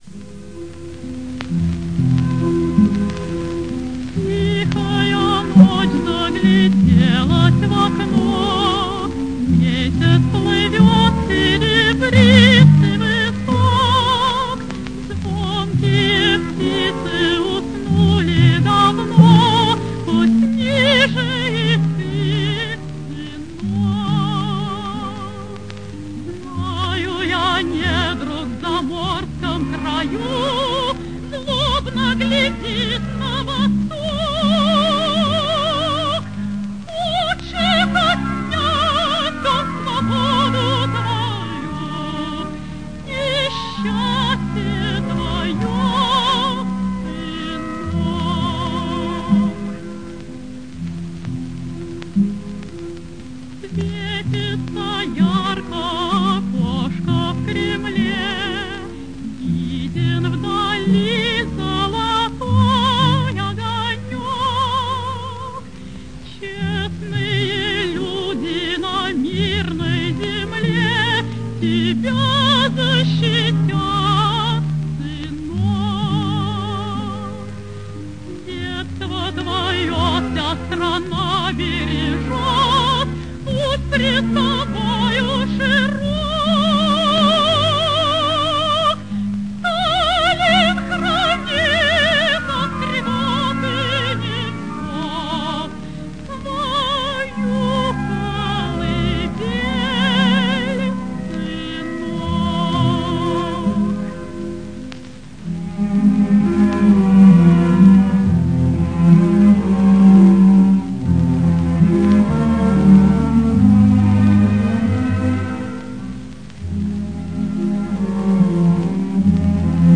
Оркестр